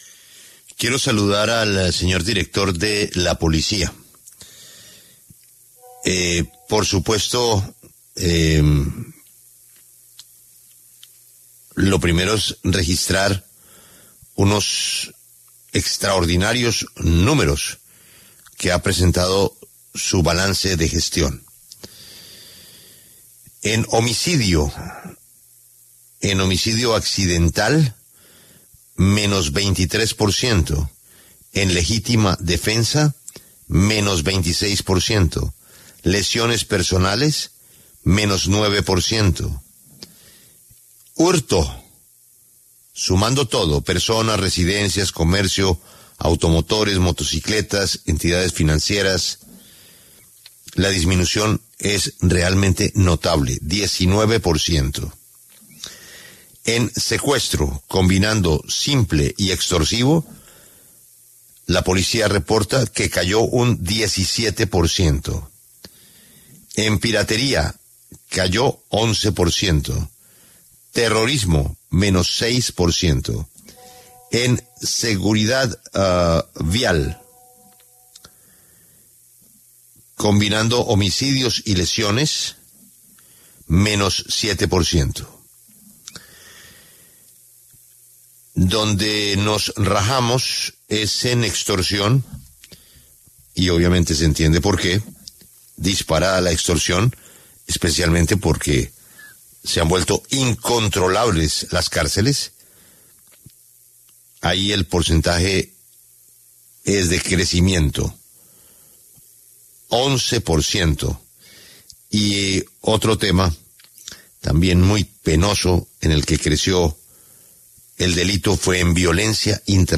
El general William René Salamanca, director de la Policía Nacional, habló en La W sobre el balance de la gestión de la entidad en 2024.